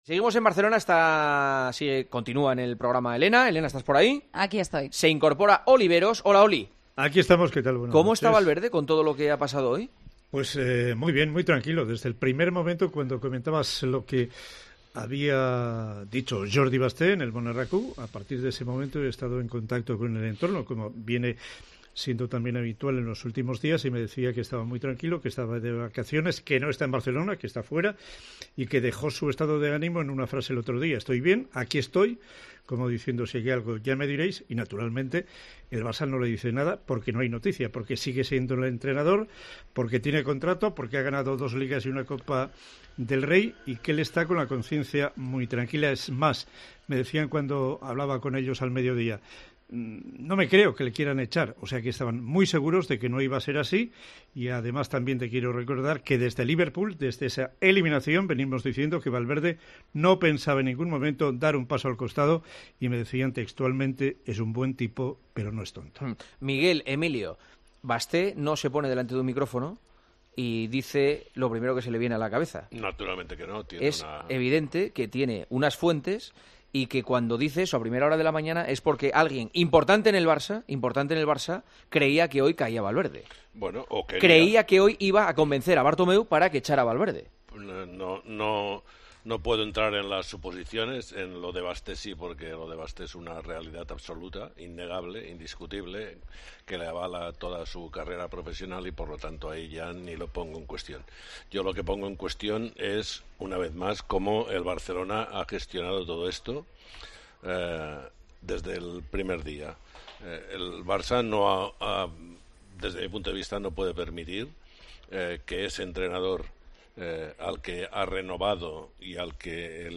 En el tiempo de tertulia de 'El partidazo de COPE'